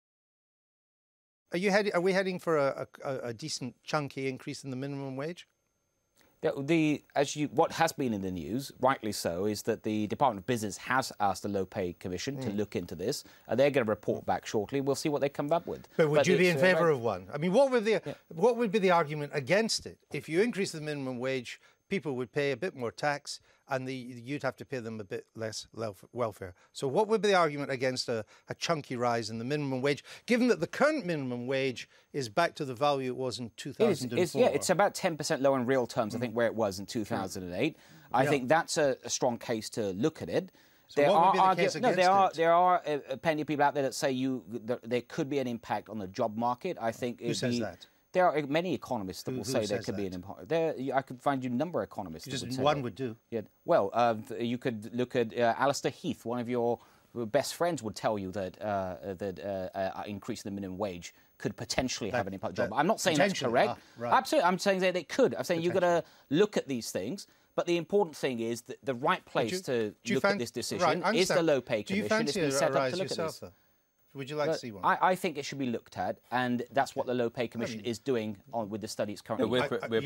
Treasury Minister Sajid Javid was asked on the Daily Politics about reports his party could support a big rise in the minimum wage.